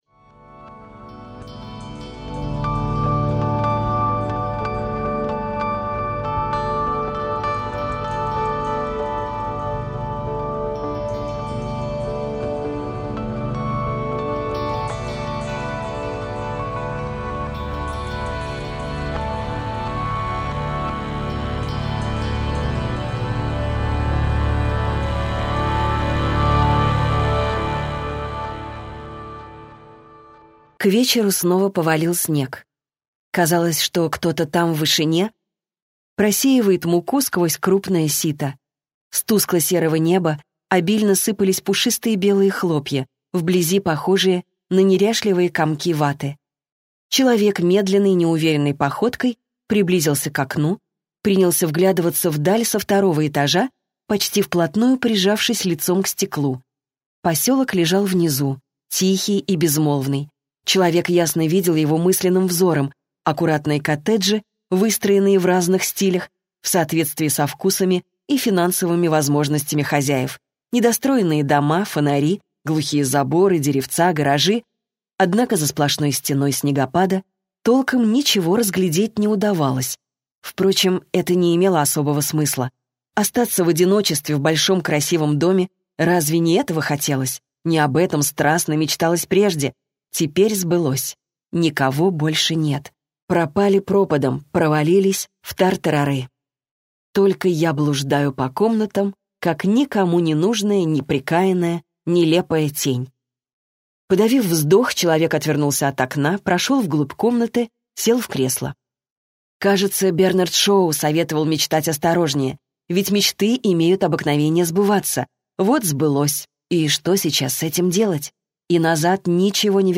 Аудиокнига Пятый неспящий - купить, скачать и слушать онлайн | КнигоПоиск